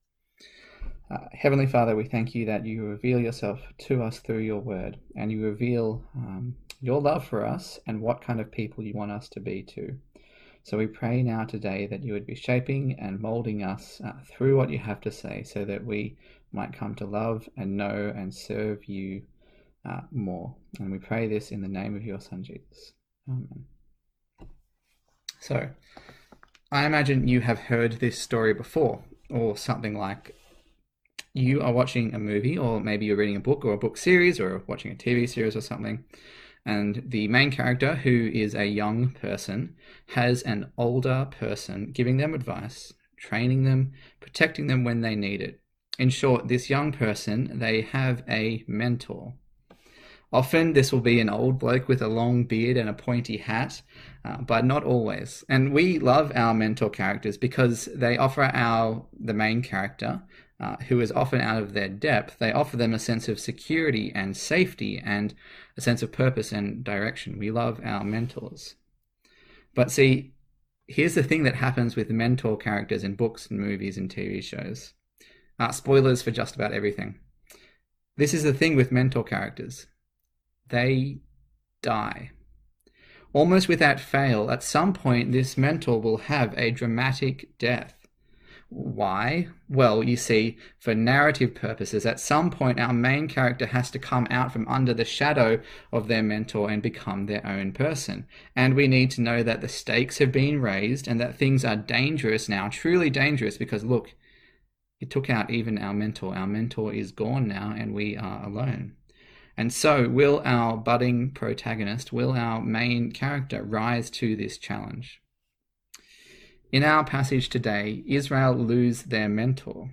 Deuteronomy Passage: Deuteronomy 31-34 Service Type: Sunday Morning